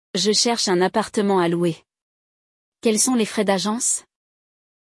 No episódio de hoje, vamos acompanhar um diálogo entre uma mulher que está procurando um apartamento e um corretor de imóveis, tudo em francês!